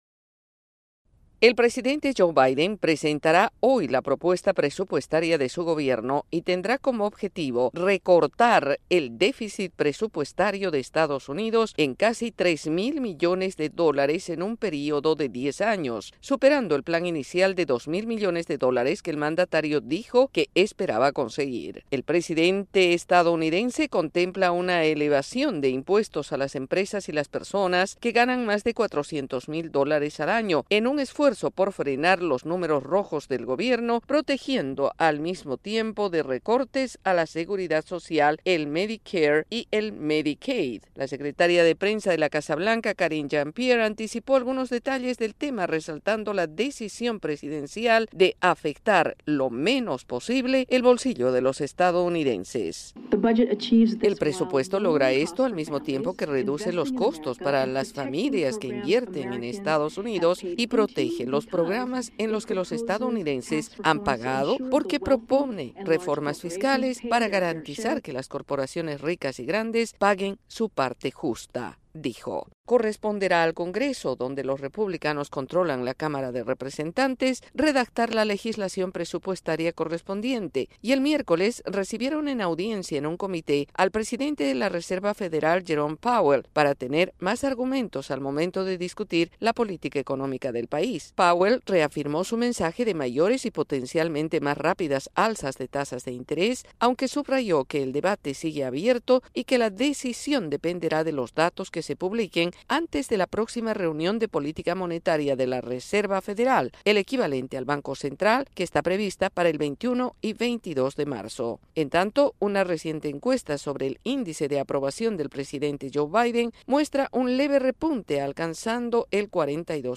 desde la Voz de América en Washington DC.